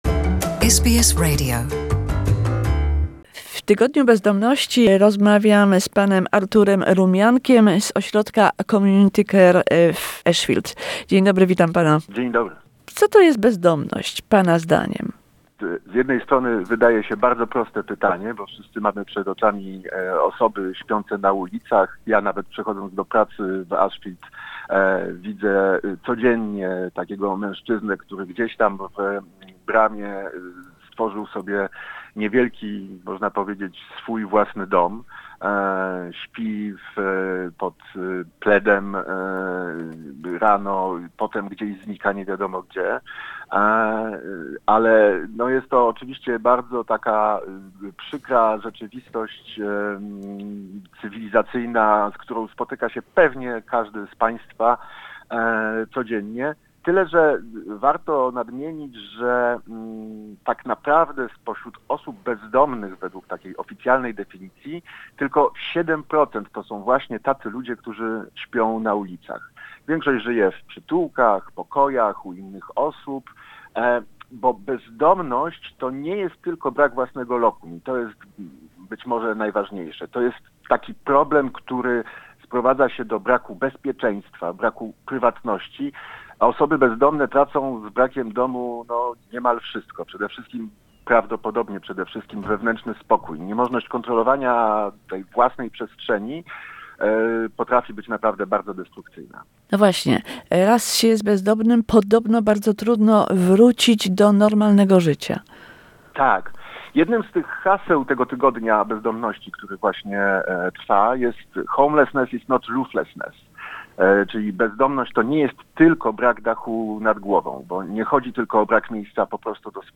Why some many people in Australia are homeless ? What help is available ? An interview